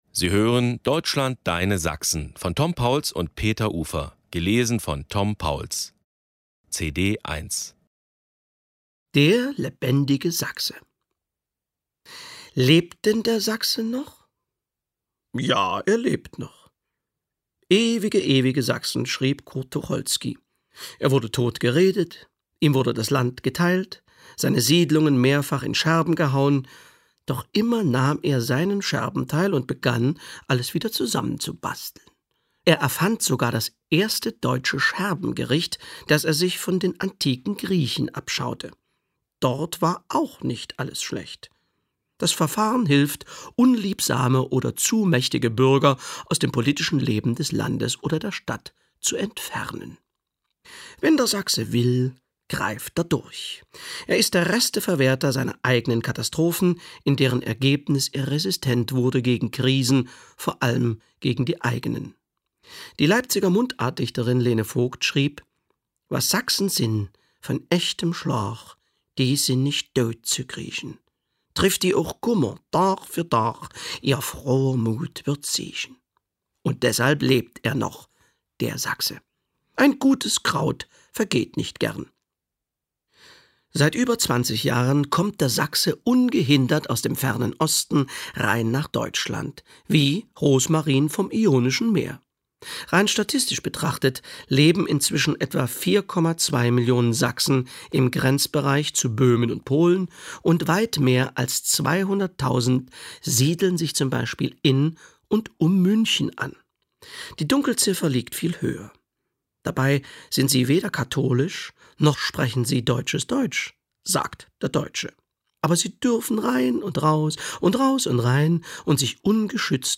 Lesung mit Tom Pauls (2 CDs)
Tom Pauls (Sprecher)
Launig erzählt er von seinen Landsleuten: Dass der Sachse lautstark alles kommentiert, ihn halb Deutschland jedoch nicht versteht, weiß jeder.